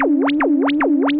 Zapps_04.wav